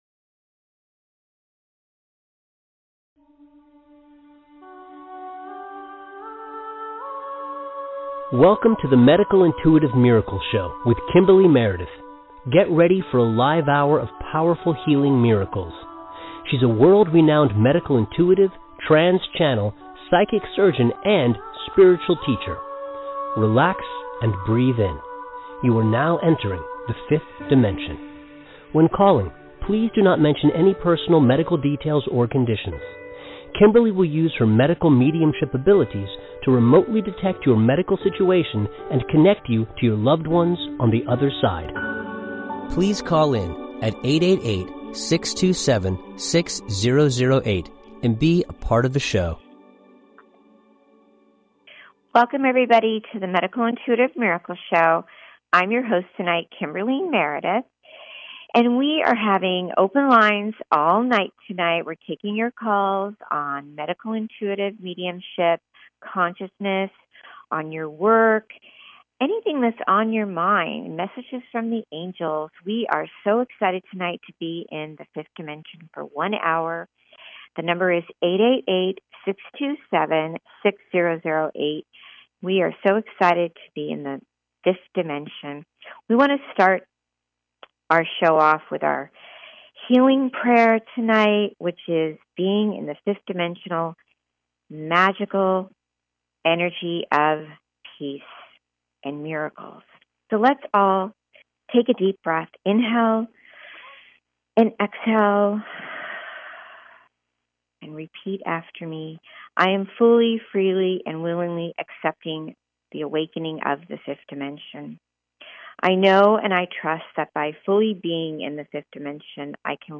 Live on air readings and special guest appearance!